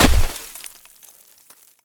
ground_place.ogg